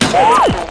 tuushin_shot01.mp3